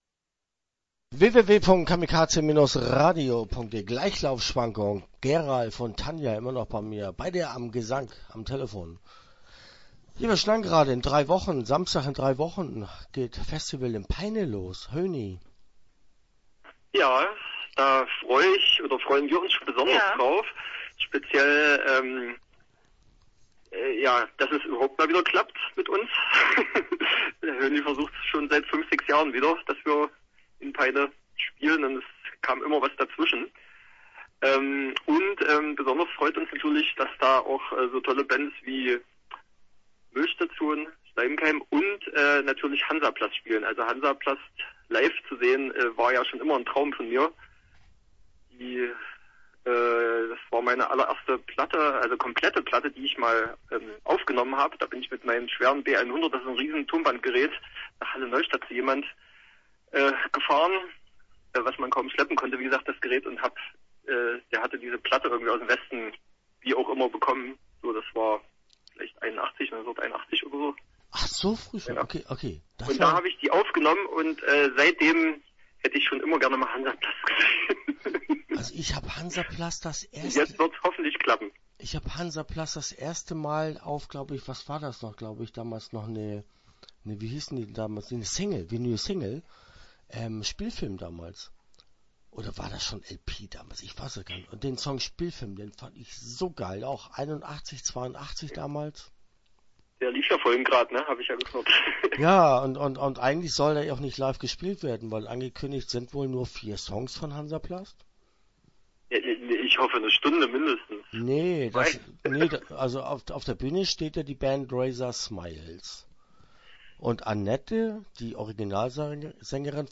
GLEICHLAUFSCHWANKUNG - Interview Teil 1 (14:17)